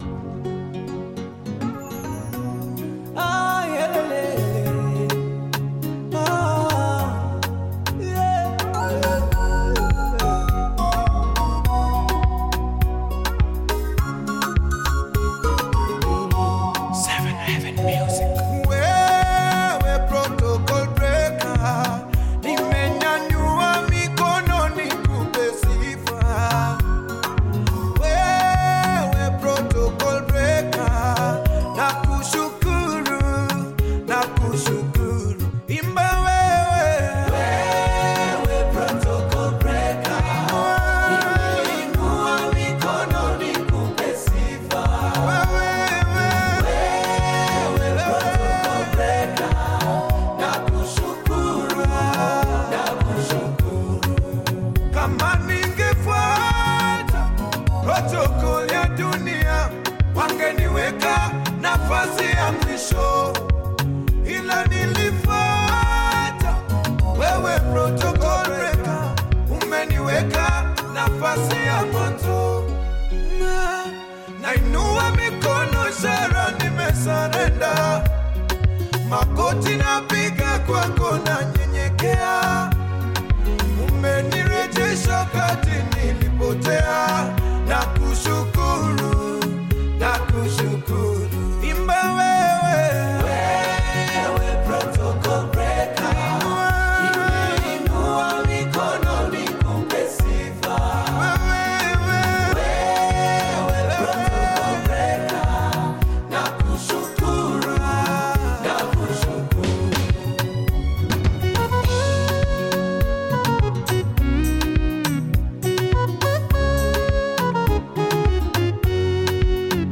Kenyan gospel single
soulful vocals
spiritually driven gospel sound